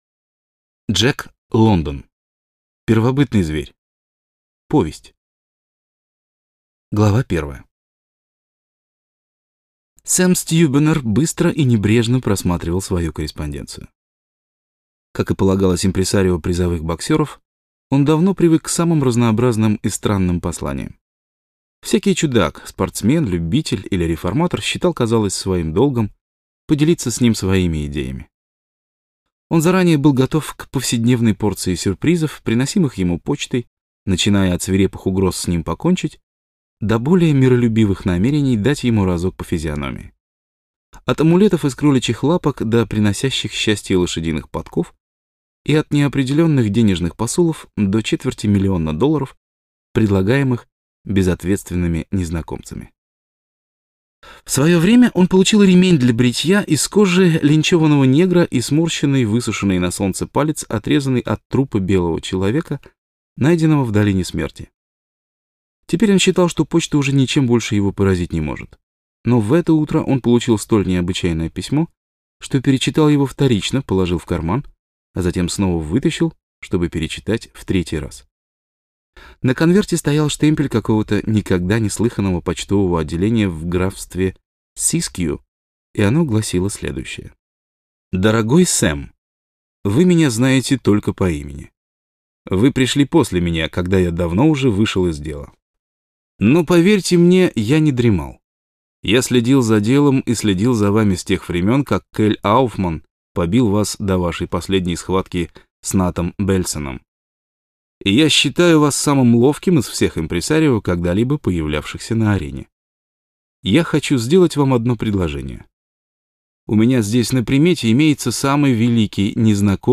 Аудиокнига Первобытный зверь | Библиотека аудиокниг